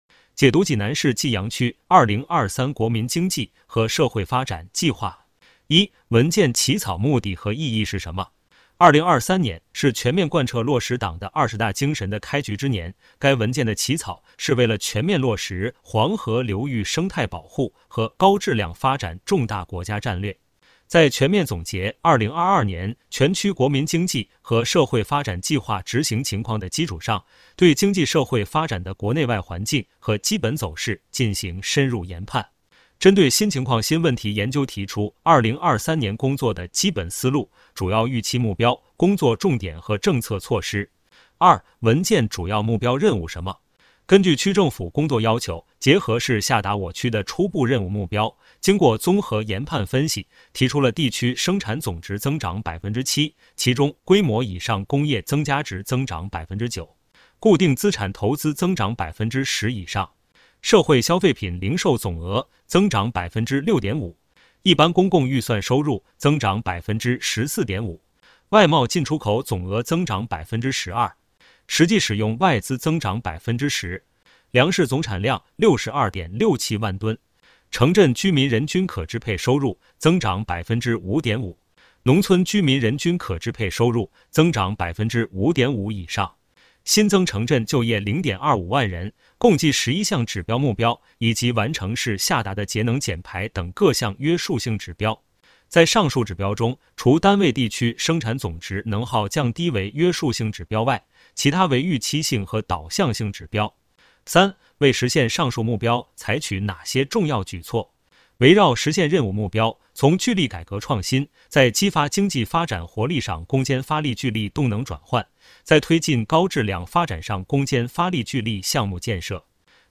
有声朗读